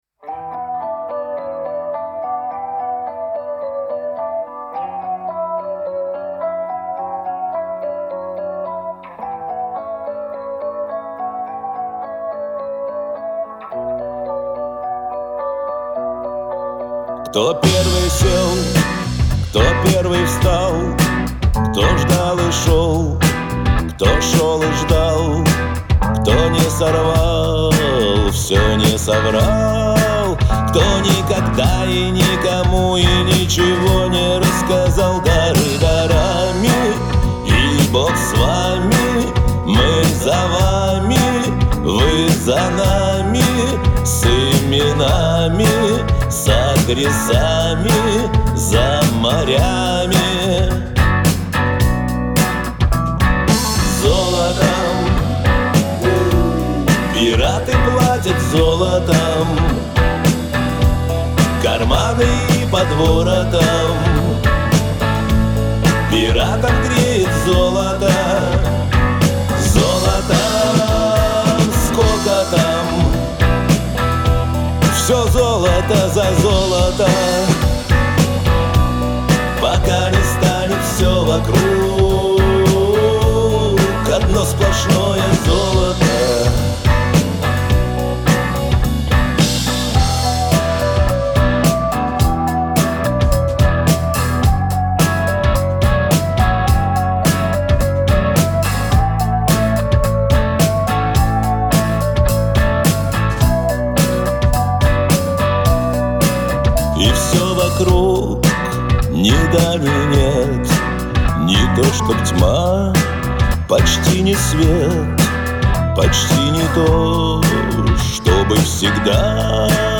Жанр: Rock, Pop